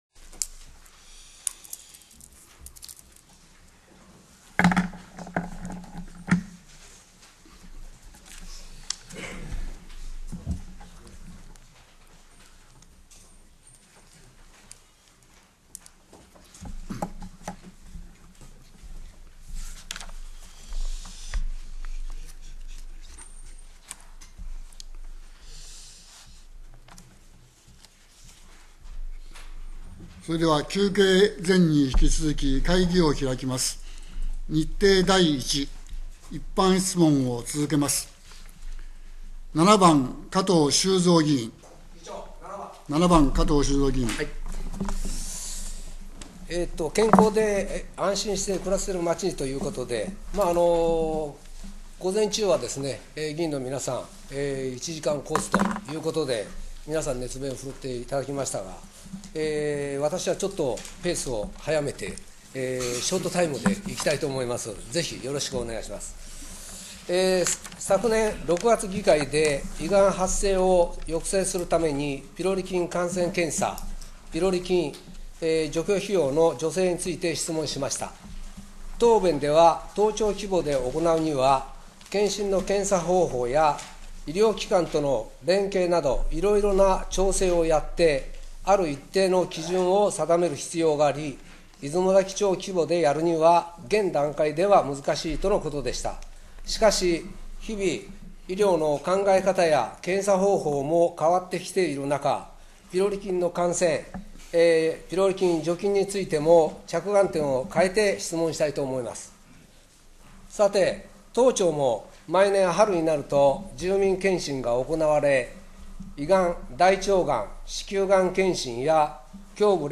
平成26年6月定例会 2日目（一般質問） | 出雲崎町ホームページ